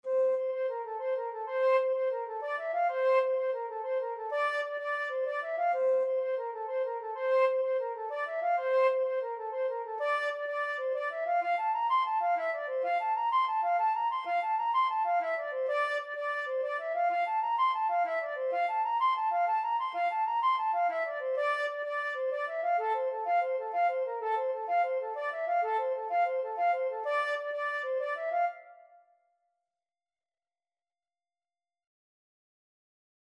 Free Sheet music for Flute
9/8 (View more 9/8 Music)
A5-C7
F major (Sounding Pitch) (View more F major Music for Flute )
Flute  (View more Easy Flute Music)
Traditional (View more Traditional Flute Music)